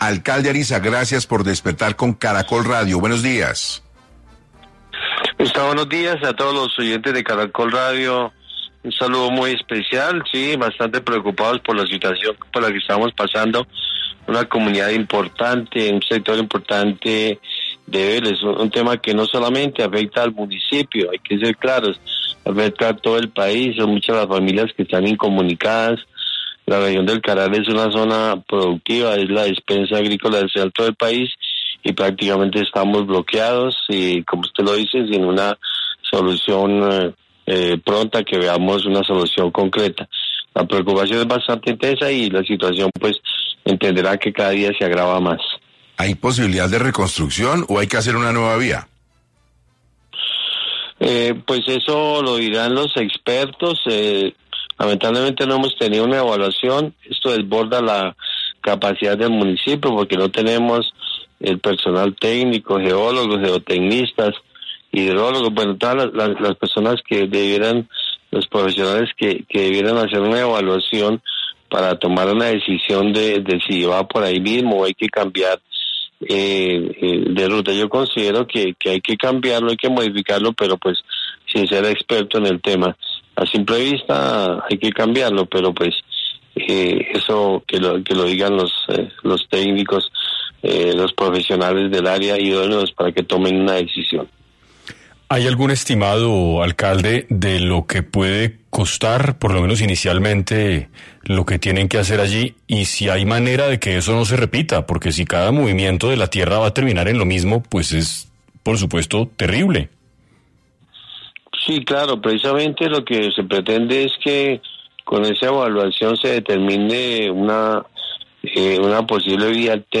Orlando Ariza, alcalde de Vélez, Santander, detalló en 6AM de Caracol Radio las consecuencias de los derrumbes que se han presentado en la troncal del Carare